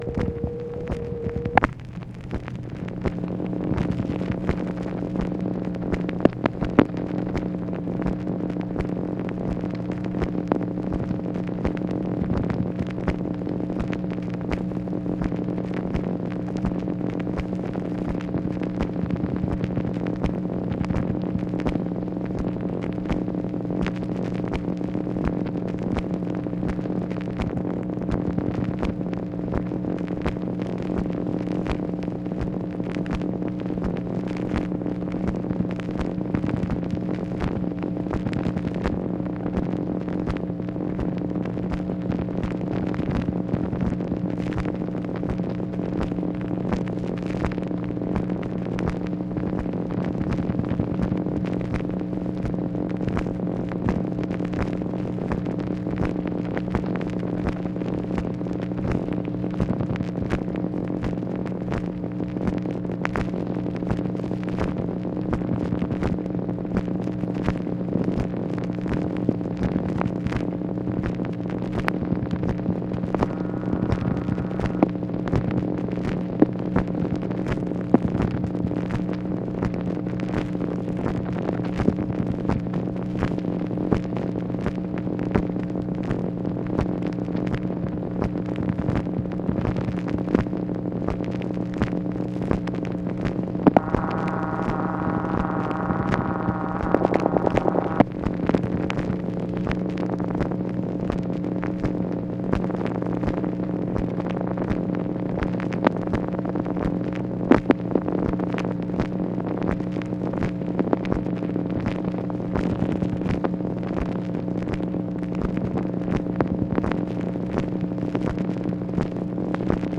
MACHINE NOISE, June 24, 1965
Secret White House Tapes | Lyndon B. Johnson Presidency